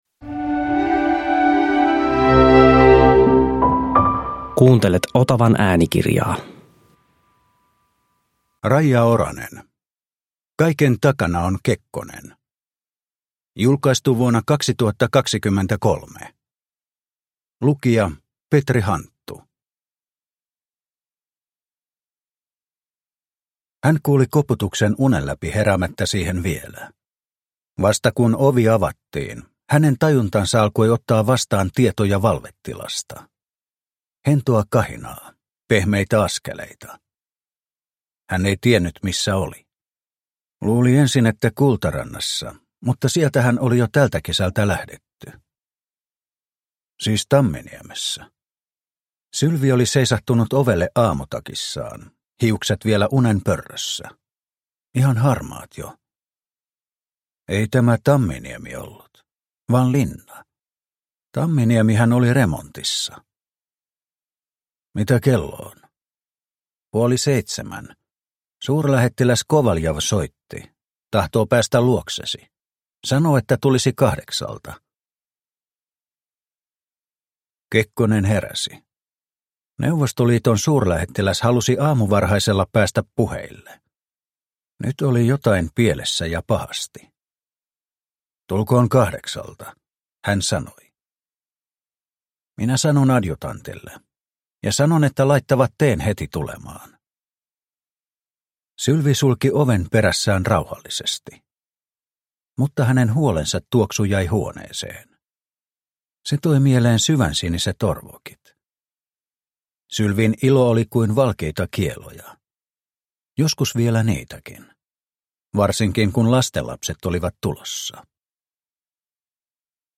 Kaiken takana Kekkonen – Ljudbok – Laddas ner